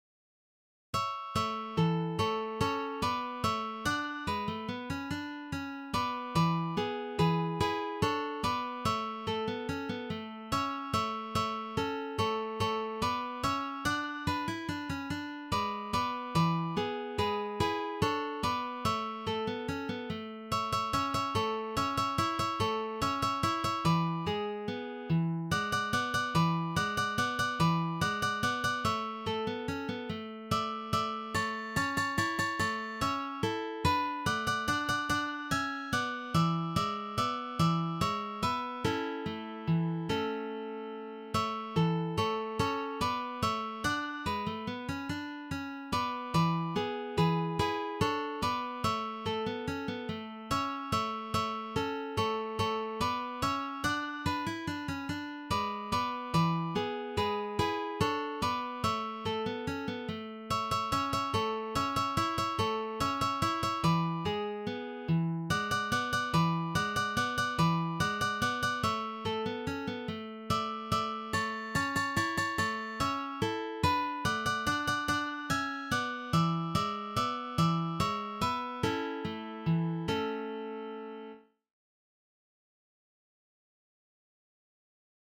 for three guitars
The tempo may be played quite fast.